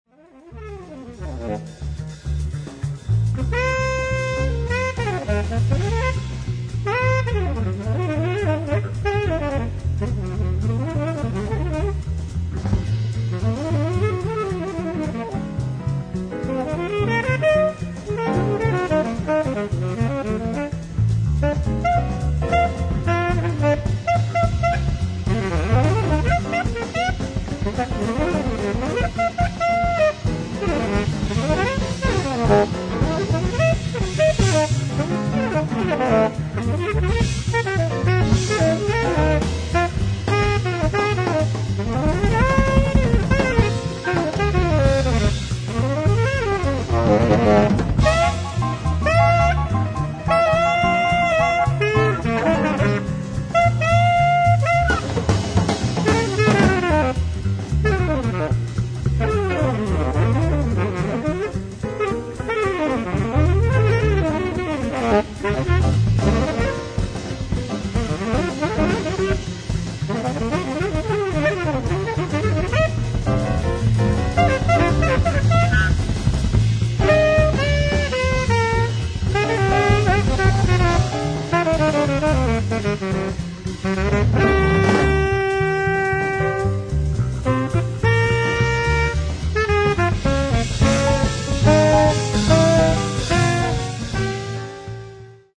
ライブ・アット・ウェストベリー・ミュージックフェアー、ウェストベリー、ニューヨーク 06/19/1992
ツアー関係者から流出したサウンドボード音源！！
※試聴用に実際より音質を落としています。